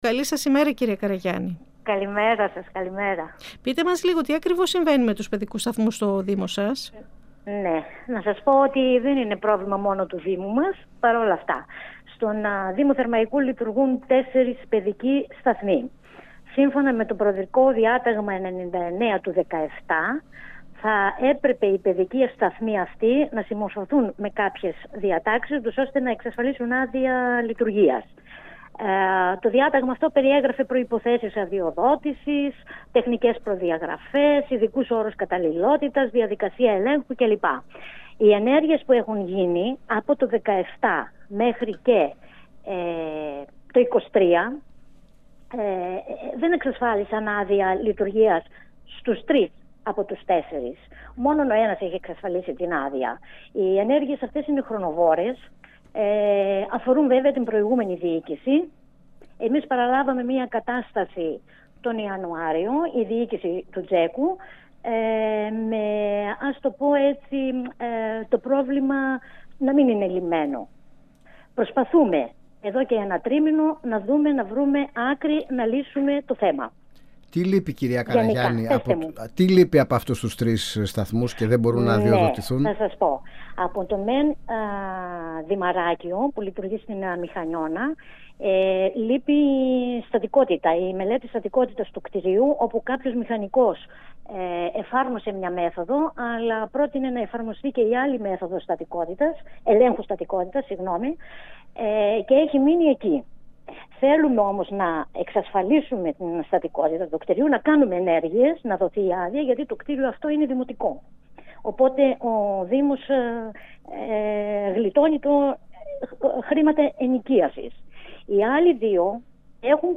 H Αντιδήμαρχος Παιδείας, Κοινωνικής Αλληλεγγύης και Ισότητας δήμου Θερμαϊκού Μορφούλα Καραγιάννη στον 102FM | «Αίθουσα Σύνταξης» | 03.04.2024
Στις προϋποθέσεις λειτουργίας των παιδικών σταθμών που βρίσκονται στη δικαιοδοσία του Δήμου Θερμαϊκού , καθώς δεν έχουν εξασφαλίσει άδεια και τρεις από αυτούς βρίσκονται «στον αέρα» αναφέρθηκε η Αντιδήμαρχος Παιδείας, Κοινωνικής Αλληλεγγύης και Ισότητας του δήμου Θερμαϊκού Μορφούλα Καραγιάννη μιλώντας στην εκπομπή «Αίθουσα Σύνταξης» του 102FM της ΕΡΤ3.